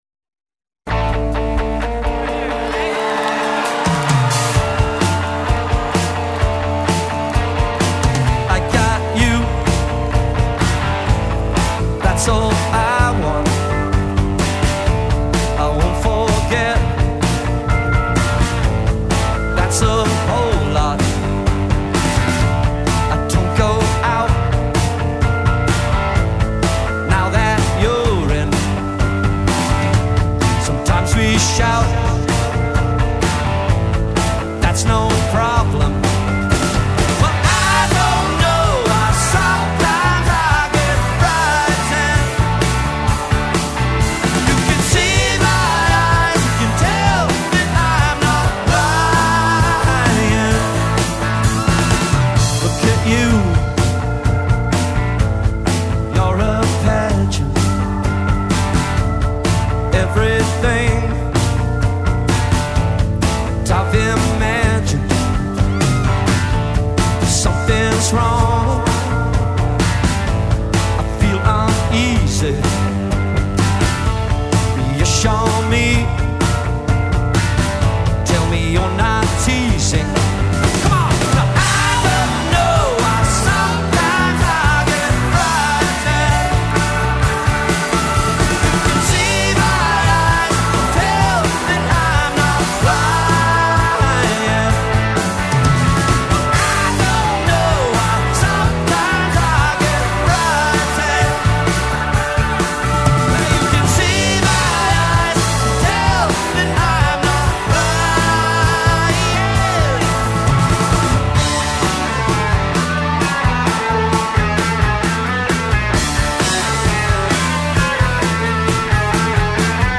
during their 2010 North American tour